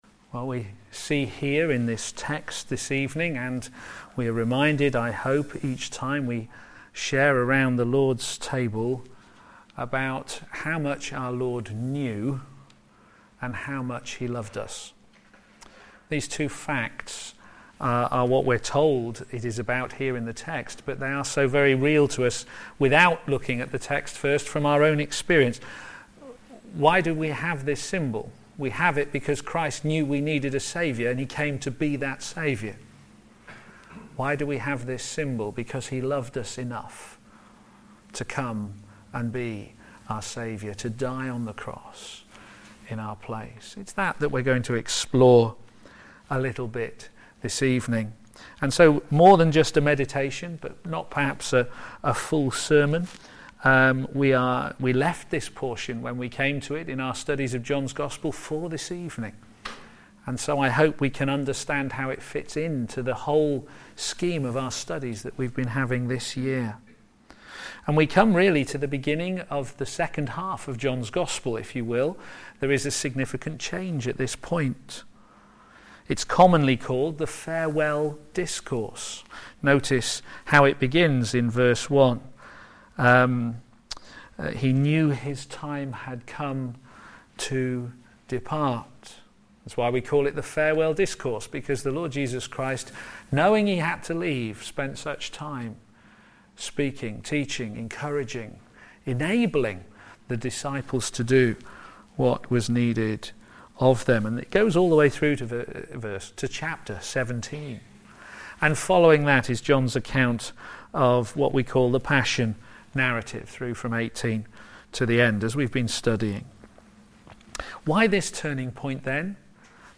p.m. Service
Theme: The Last Supper Sermon